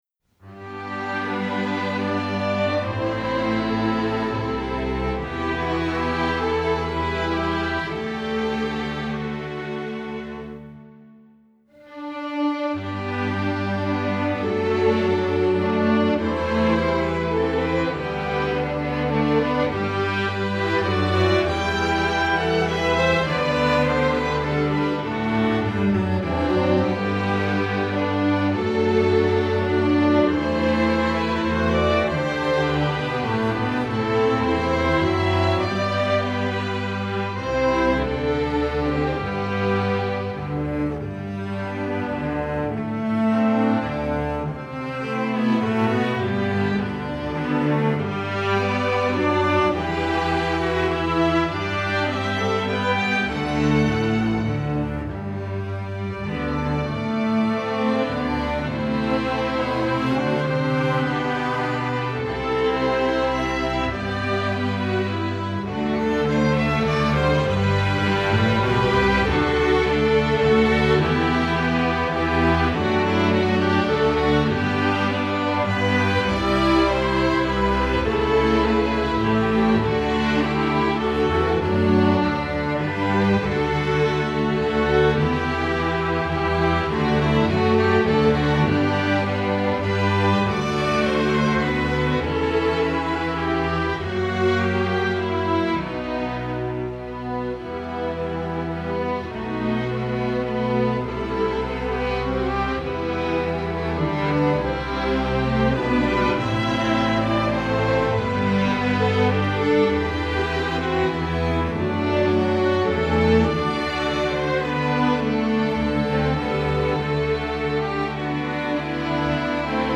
Instrumentation: string orchestra
instructional, children